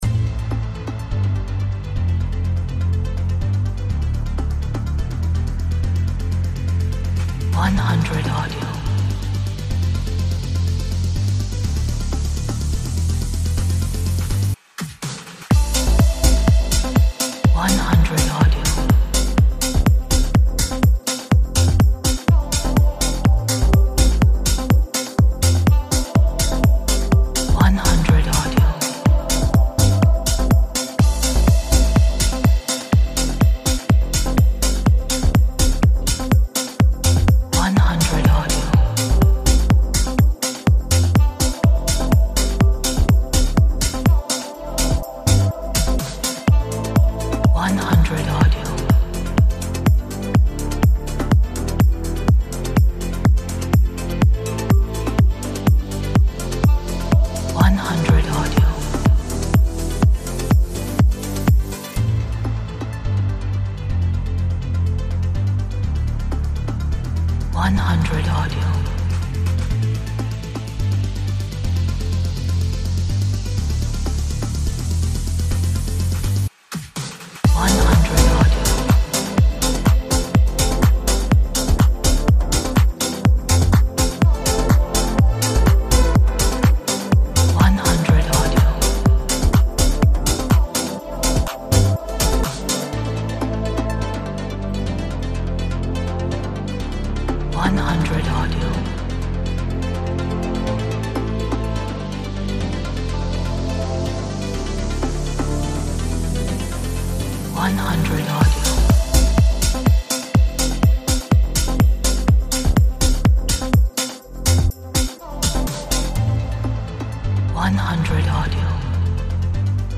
节奏感 潮流 科技